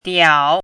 diao3.mp3